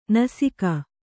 ♪ nasika